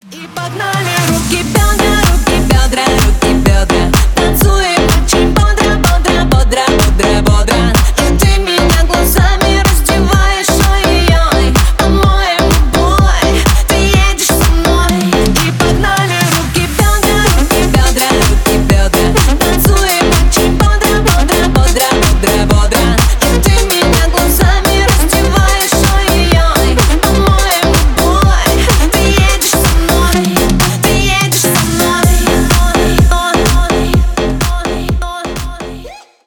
Поп Музыка # Танцевальные